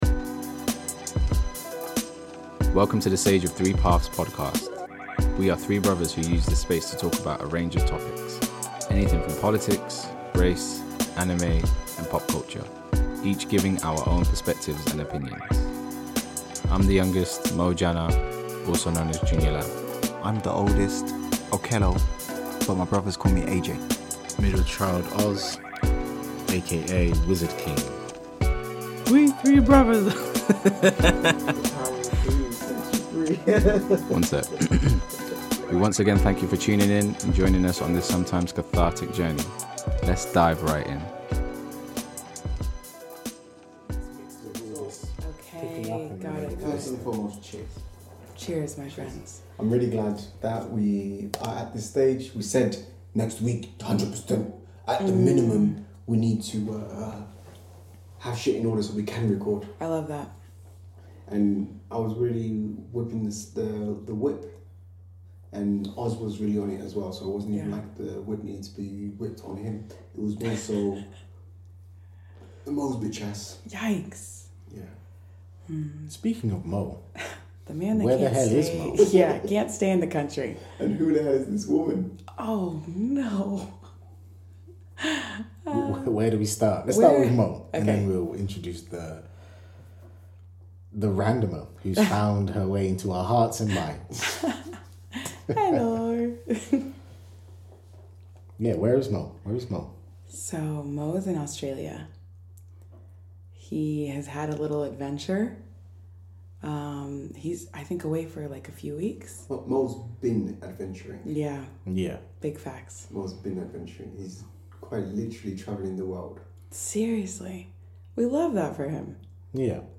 This is a fun conversation that, as always, has gems and controversial topics littered throughout for you to masticate and think on.